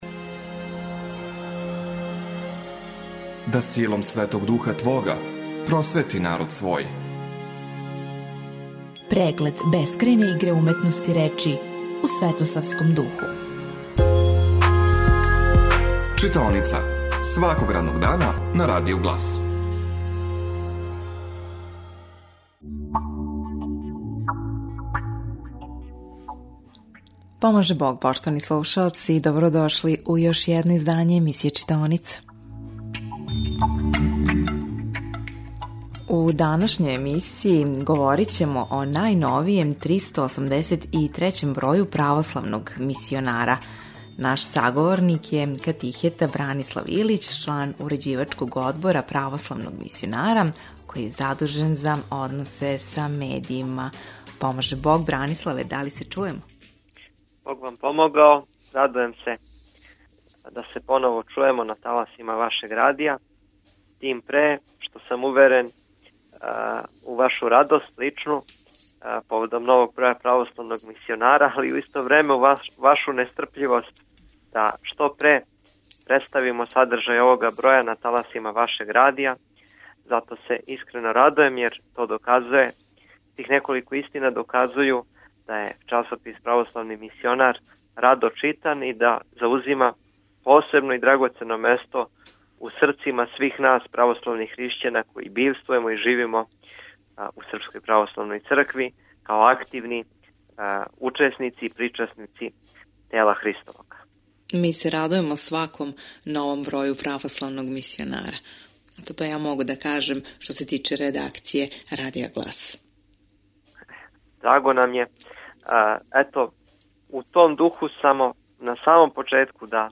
Звучни запис разговора Извор: Радио Глас